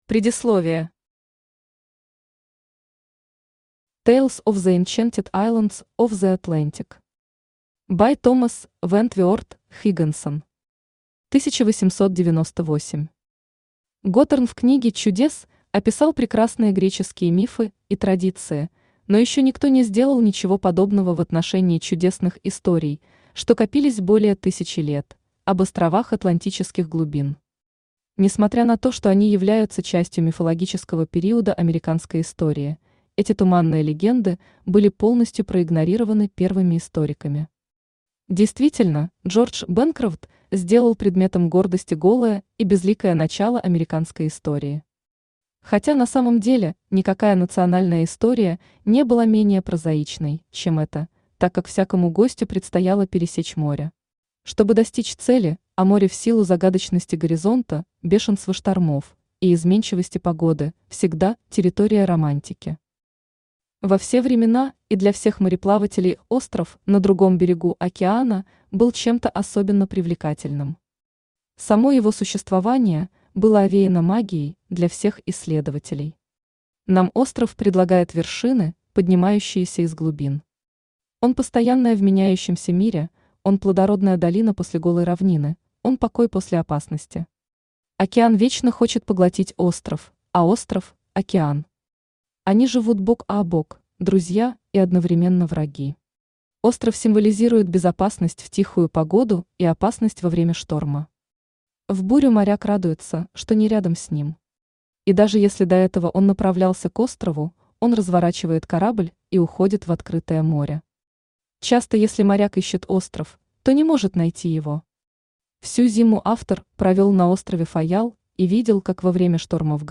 Аудиокнига Легенды зачарованных островов Атлантики | Библиотека аудиокниг
Aудиокнига Легенды зачарованных островов Атлантики Автор Томас Вентворт Хиггинсон Читает аудиокнигу Авточтец ЛитРес.